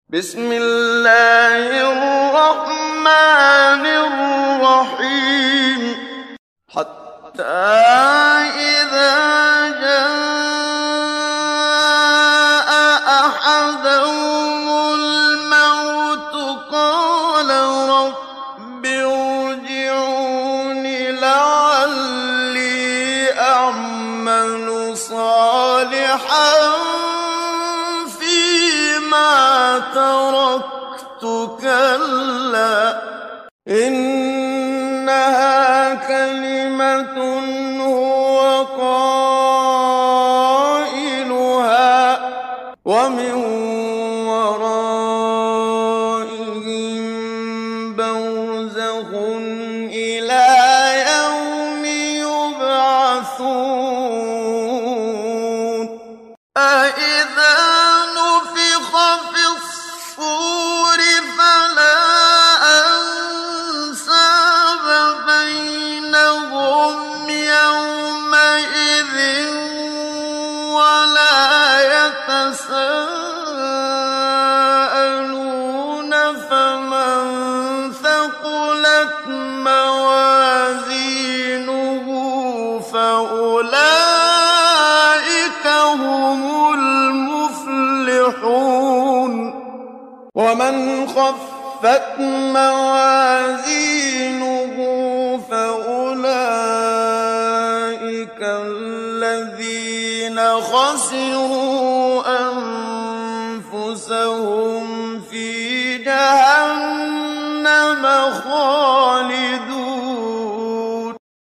قرآن كريم -سورة المؤمنون بصوت الشيخ محمد صديق المنشاوي